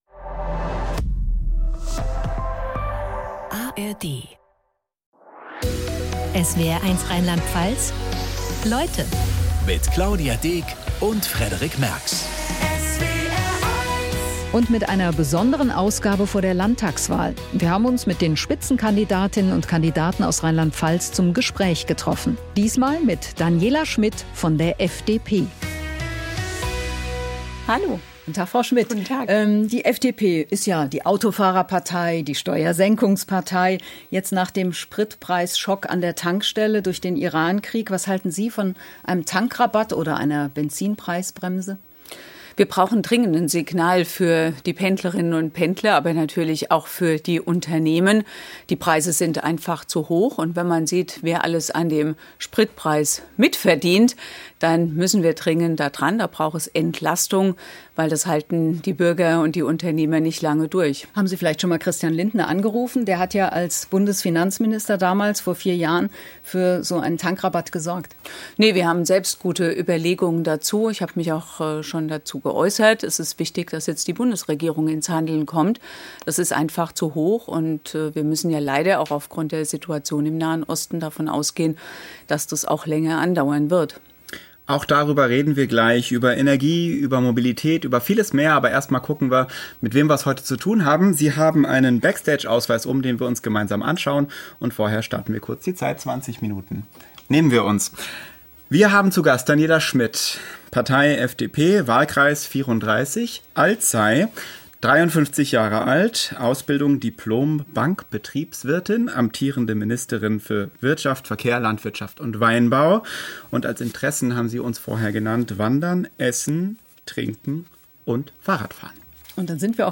Anlässlich der Landtagswahl 2026 in Rheinland-Pfalz sendet der SWR multimediale Interviews mit den Spitzenkandidatinnen und -kandidaten von SPD, CDU, Grünen, AfD, Freien Wählern, Linken und FDP.